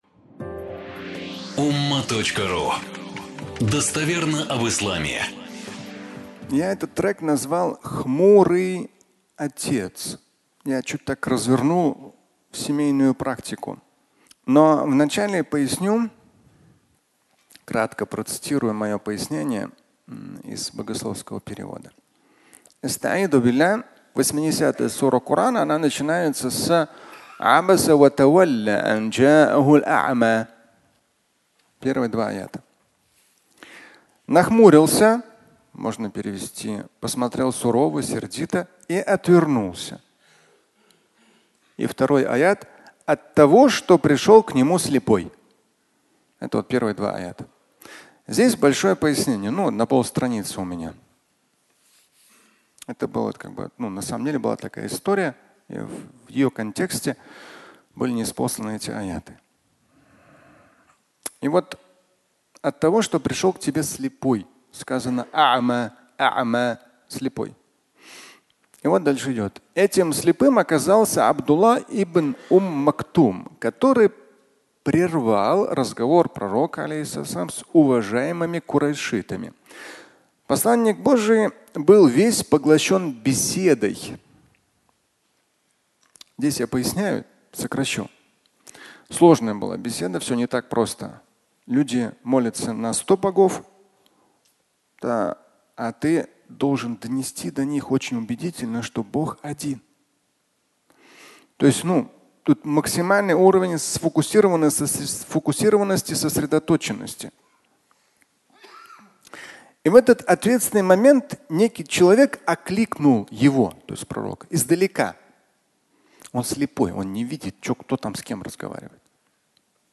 Аудио статья
Пятничная проповедь